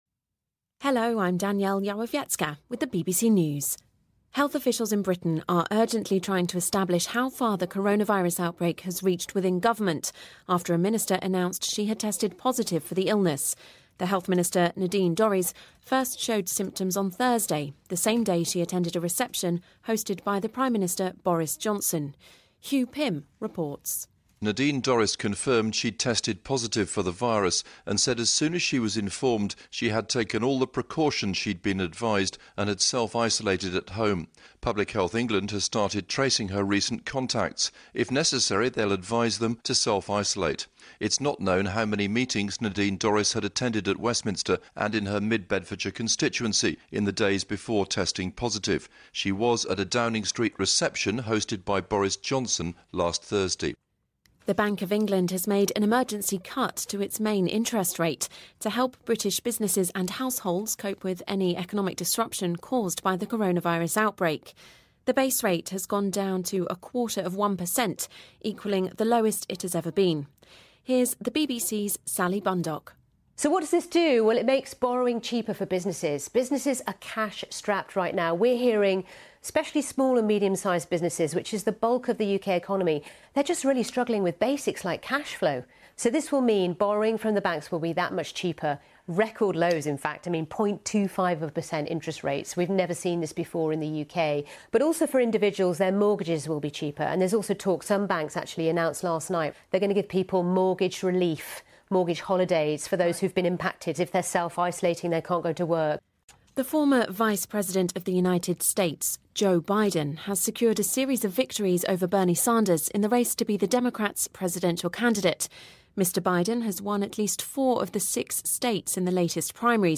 News
英音听力讲解:英国卫生部高官感染新冠肺炎